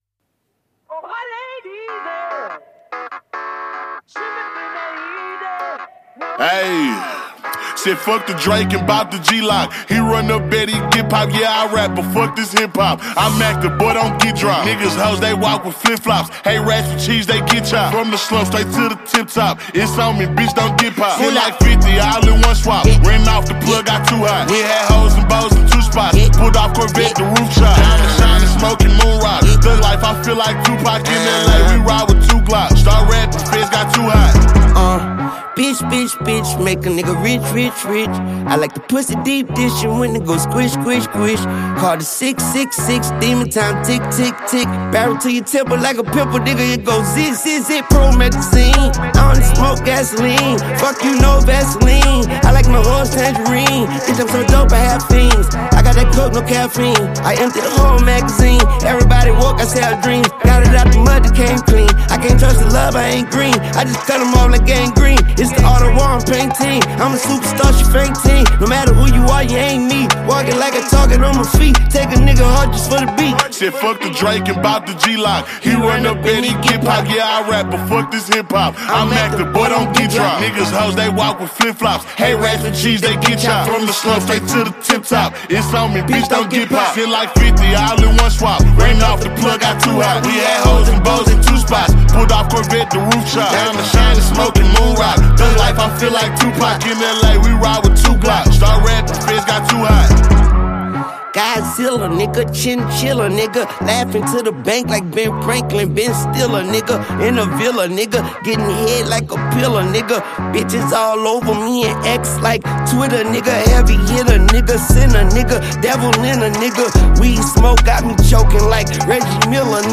smooth beats
His flow is still creative and full of energy.